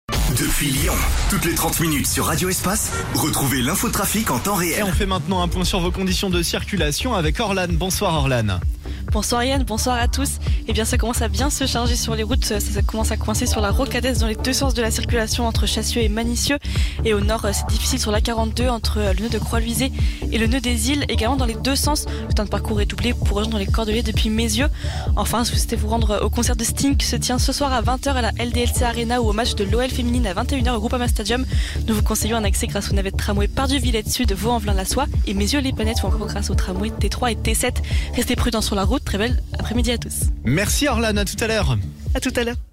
Info trafic